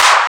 CLAP     2-R.wav